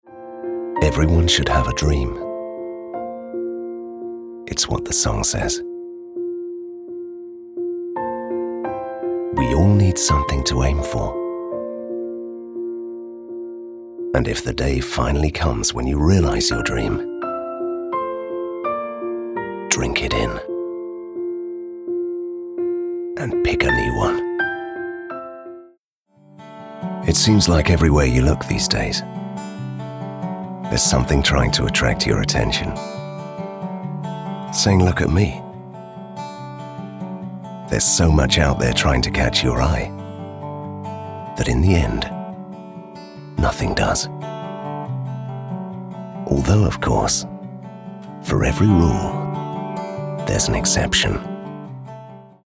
Soft